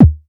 REDD PERC (40).wav